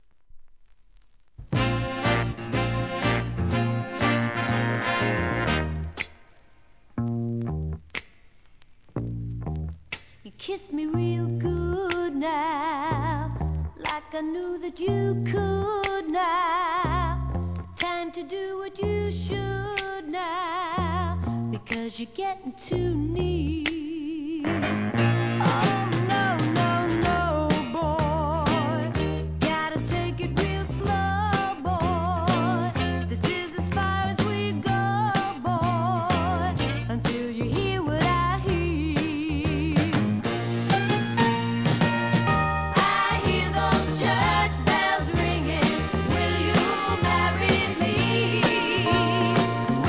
¥ 550 税込 関連カテゴリ SOUL/FUNK/etc...
MONO